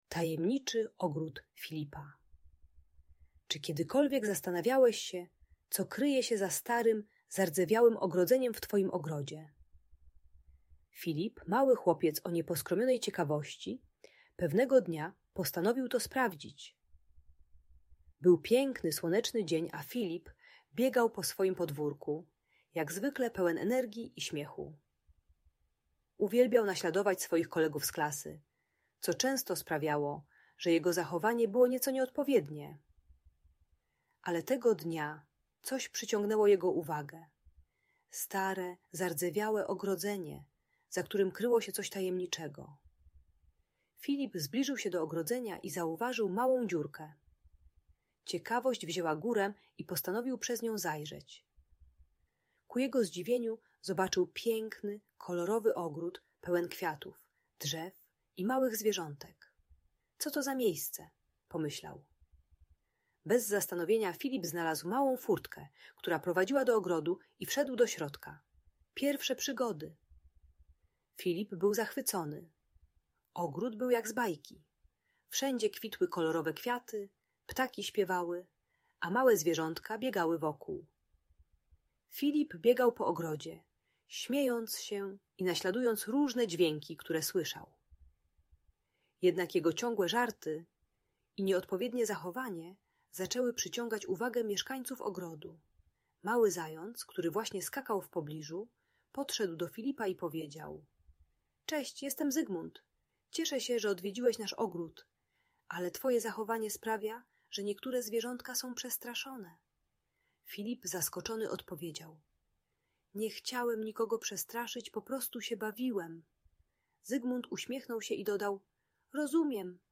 Tajemniczy Ogród Filipa - historia o przygodach i odpowiedzialności - Audiobajka